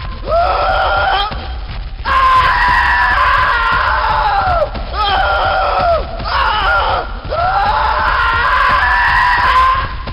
Scream.ogg